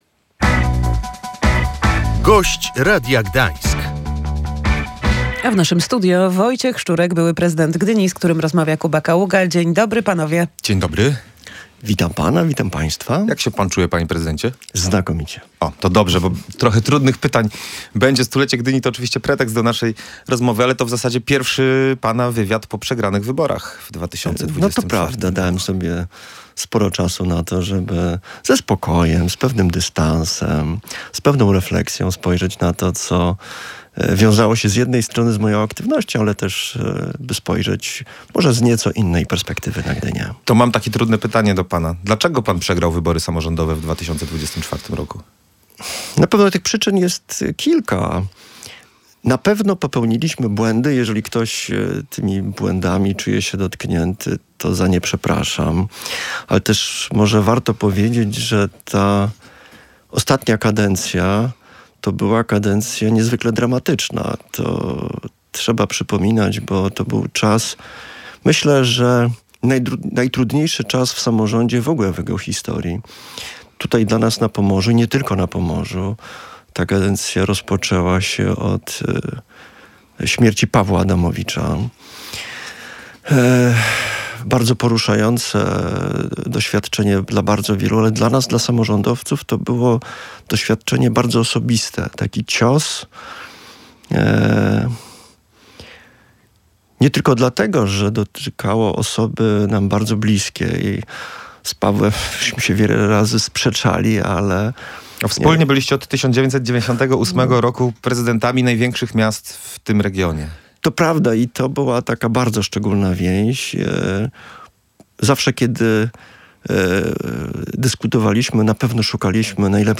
W studiu Radia Gdańsk Wojciech Szczurek zapowiedział, że nie porzucił planów o pracy dla miasta.